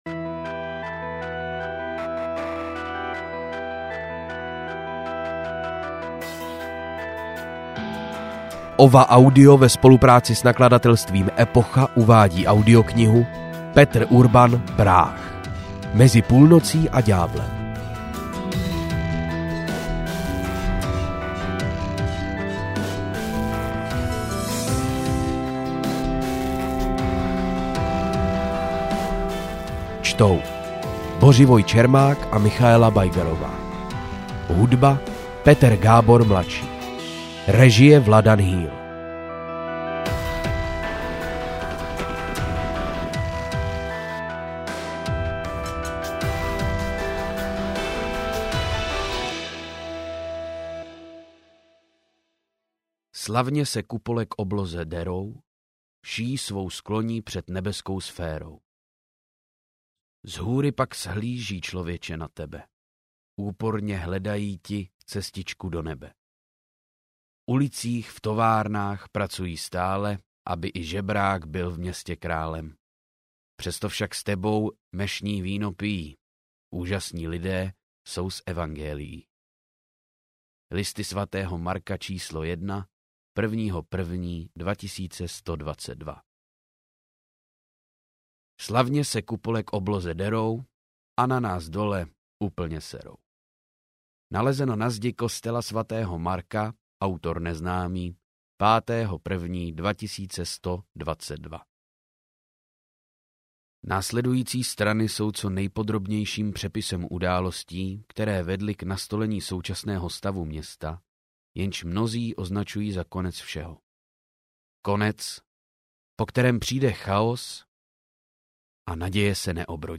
Práh: Mezi půlnocí a ďáblem audiokniha
Ukázka z knihy